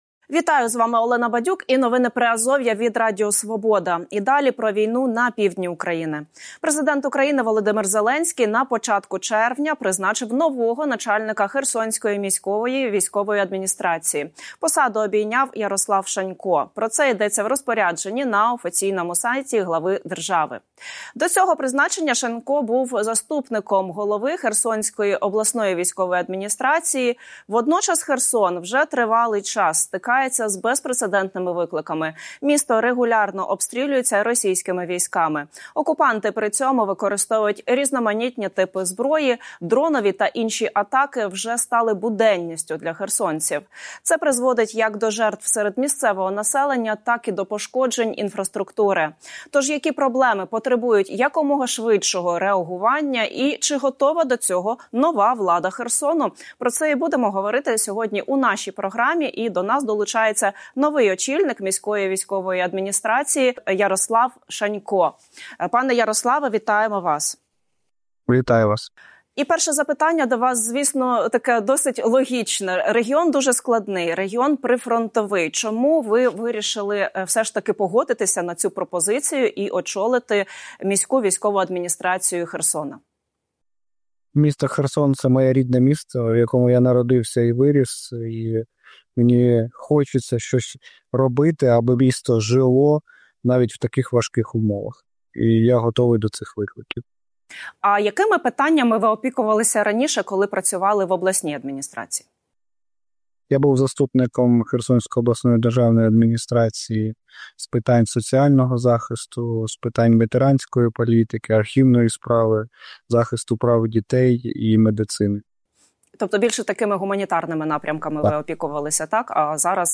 Інтерв'ю з новим начальником Херсонської МВА Ярославом Шаньком | | Новини Приазов'я